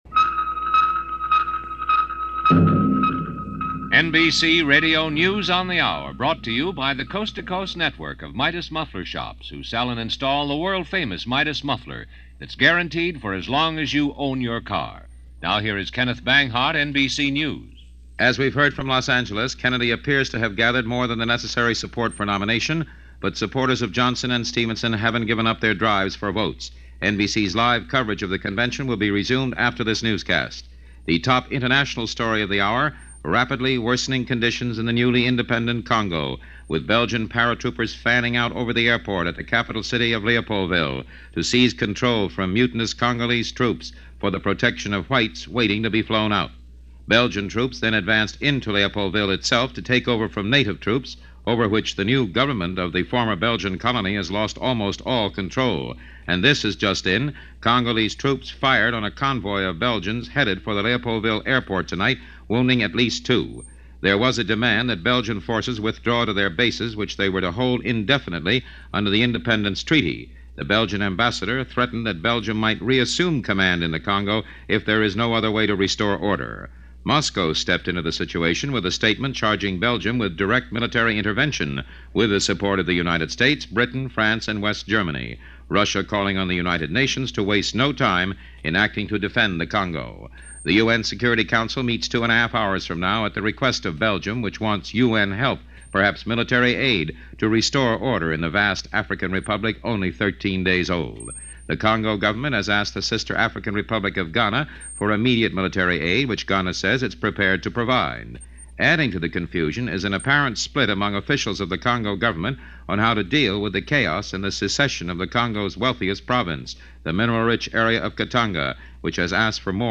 And that’s just a bit of what happened on this July 13, 1960, as reported by NBC Radio News On The Hour.